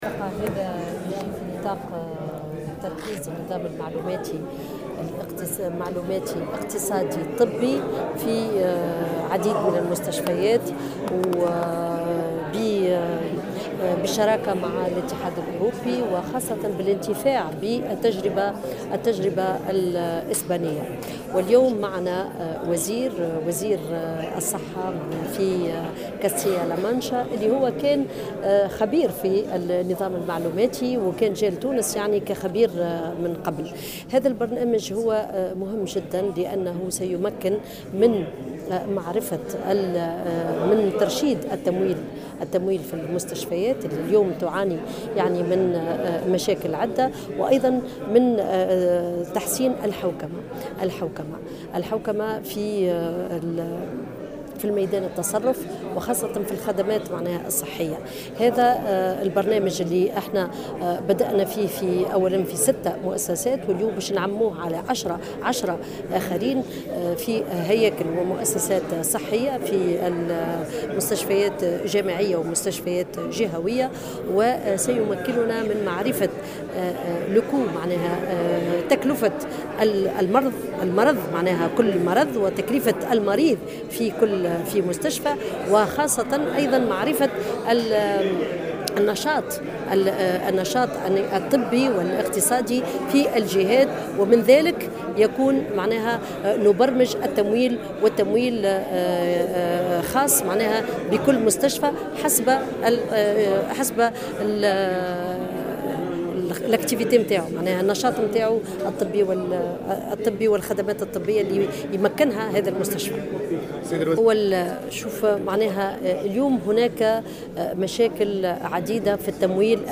أكدت وزيرة الصحة سميرة مرعي في تصريح لمراسل الجوهرة "اف ام" اليوم الخميس 17 نوفمبر 2016 أن الوزارة تعمل بالشراكة مع الإتحاد الأوروبي وأسوة بالتجربة الإسبانية خاصة على تركيز نظام معلوماتي اقتصادي طبي في عدة مؤسسات استشفائية تونسية.